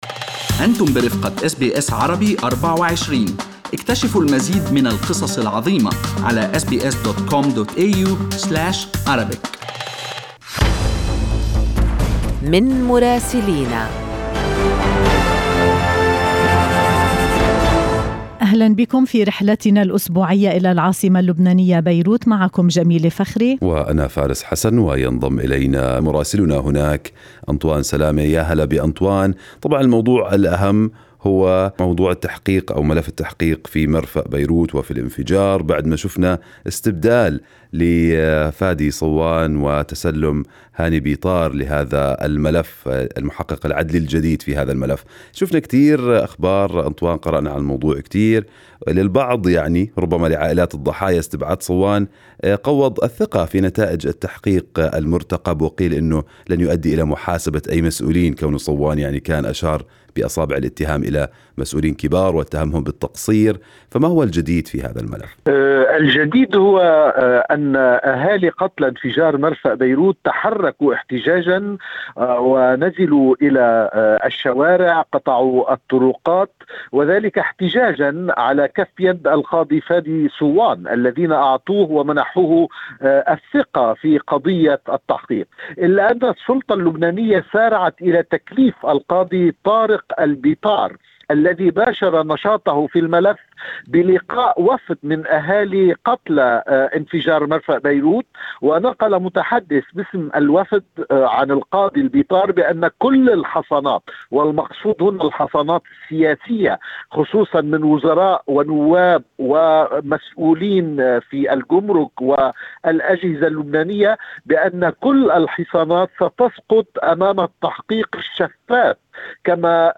أهم أخبار الدول العربية مع مراسلينا من لبنان ومصر والأراضي الفلسطينية والعراق والولايات المتحدة.